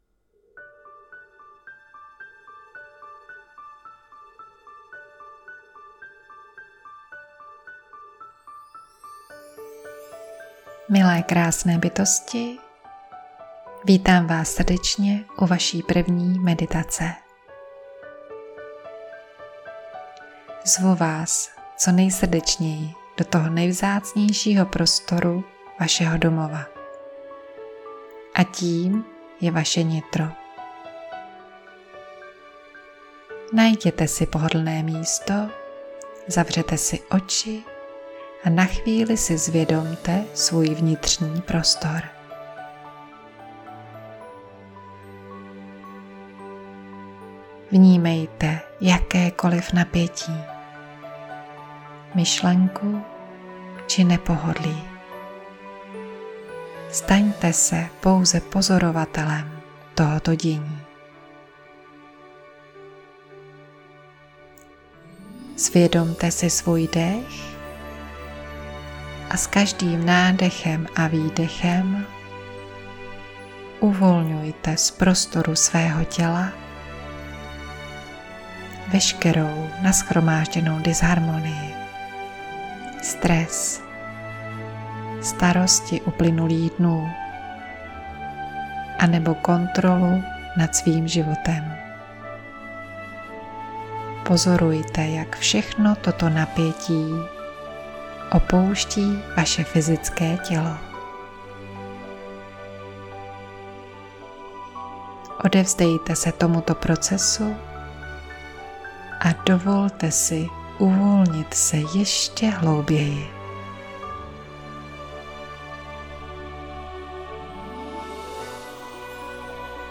Meditace Vzhůru do sedmého nebe mp3